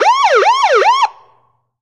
alarmPoliceSiren.ogg